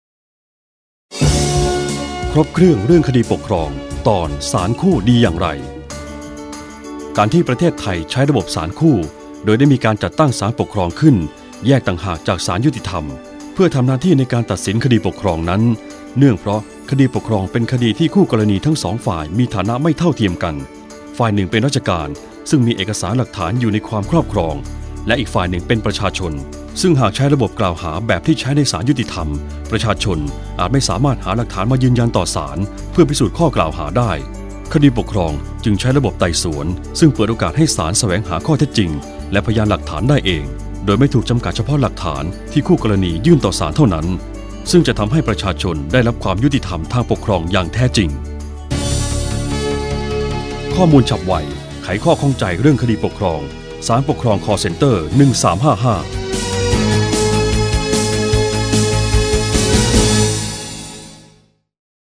สารคดีวิทยุ ชุดครบเครื่องเรื่องคดีปกครอง ตอนศาลคู่ดีอย่างไร